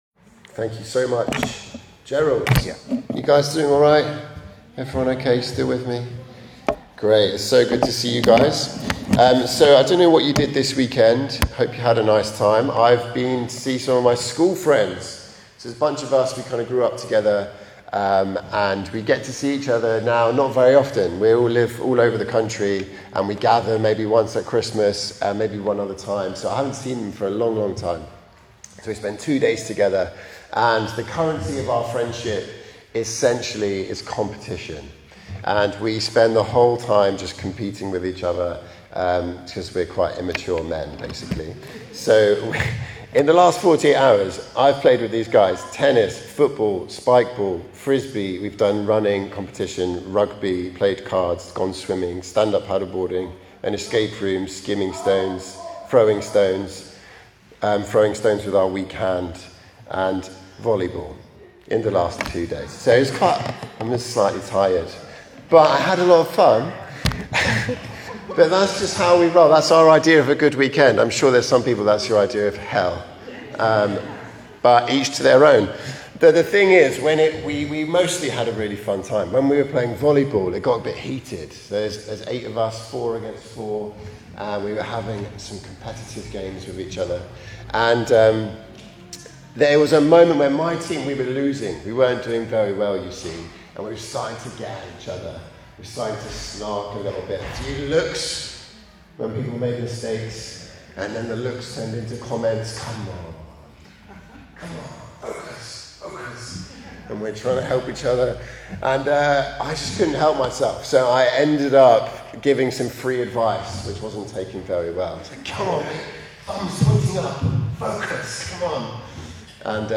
Talk from 7th September 2025 - News - St John's Park, Sheffield - A Church Near You